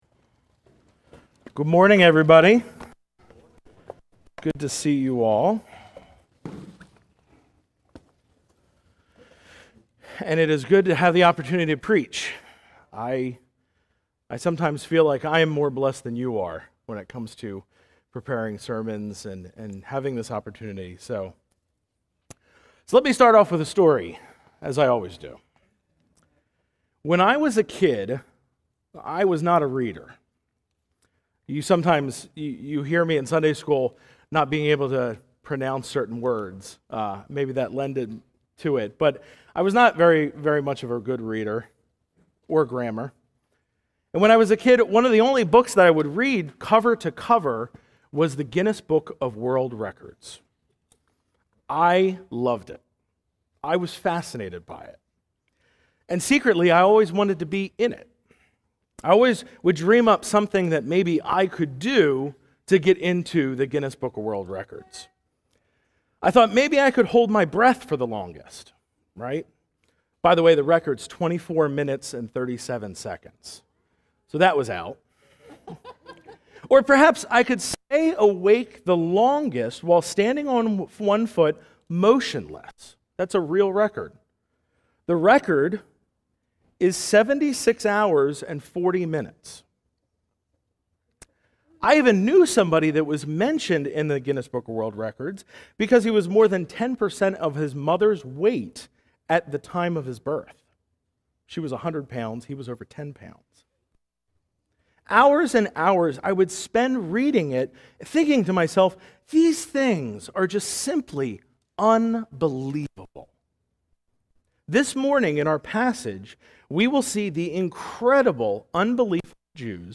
A message from the series "Exposition of Ephesians."